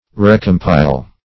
Recompile \Re`com*pile"\ (r[=e]`k[o^]m*p[imac]l"), v. t. To compile anew.